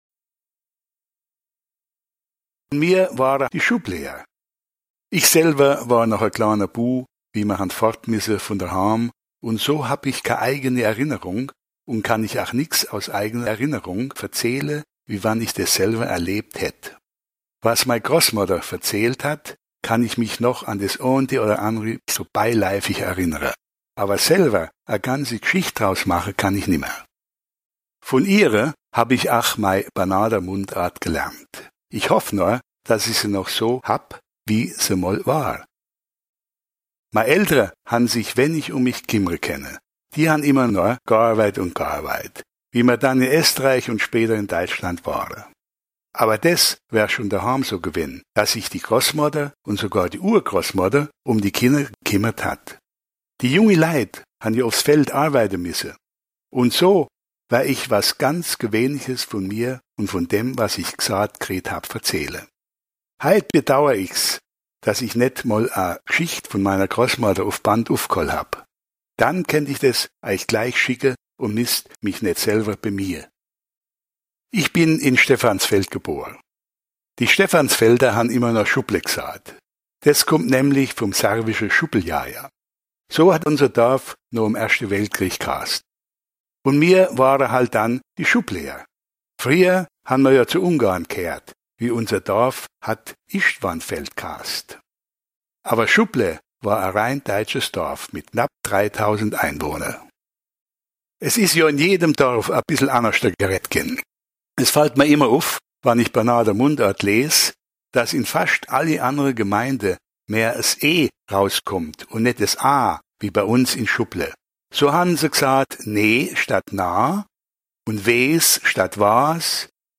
Contes et récits en dialecte enregistrés dans les communes de Veckersviller, Vieux Lixheim, Bettborn, Fénétrange, Mittersheim, Niederstinzel et Krajisnik/Stefansfeld (Banat).
Stefansfeld Banat